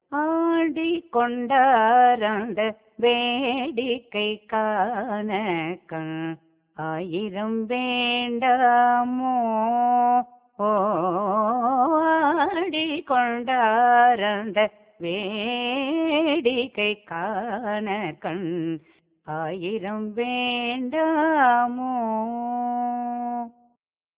இராகம் : மாயாமாளவகௌள தாளம் : ஆதி